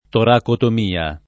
toracotomia [ torakotom & a ] s. f. (med.)